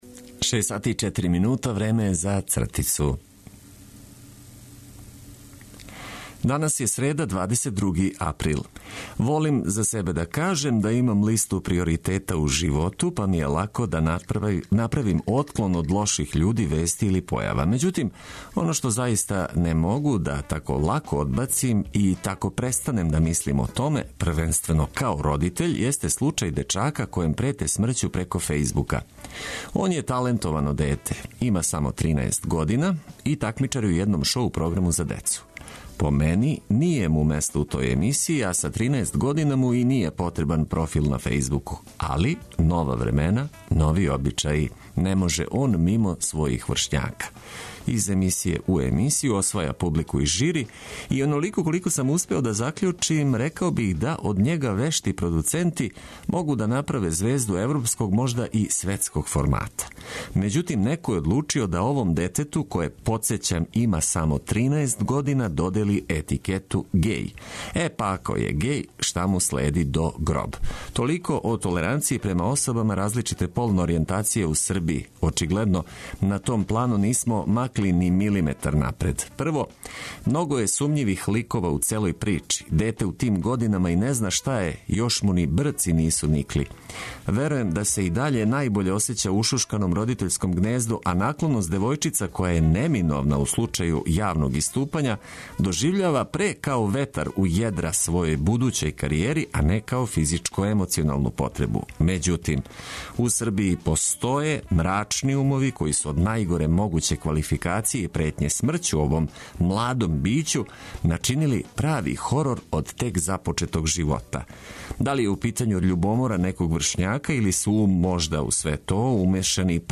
Нека јутро почне уз добру музику коју ћемо прошарати информацијама од којих вас неће заболети глава.